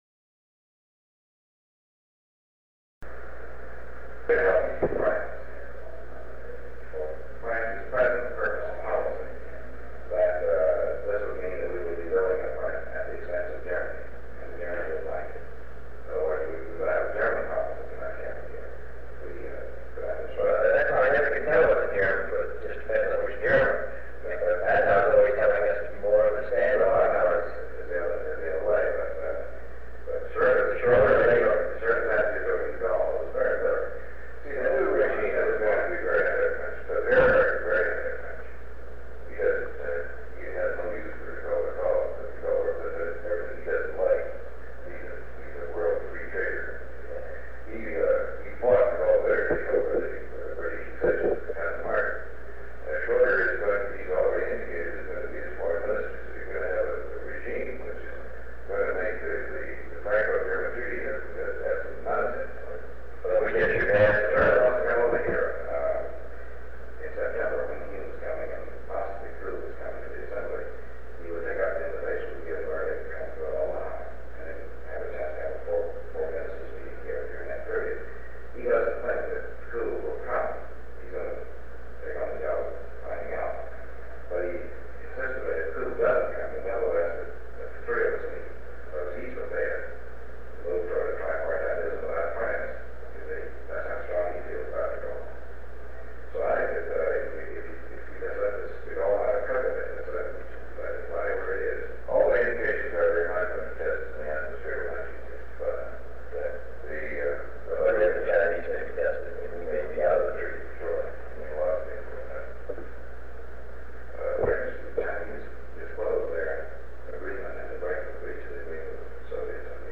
Sound recording of a meeting held on August 16, 1963, between President John F. Kennedy, Secretary of State Dean Rusk, and Under Secretary of State George Ball. They discuss German leadership, France, nuclear testing, foreign aid, and Cuba.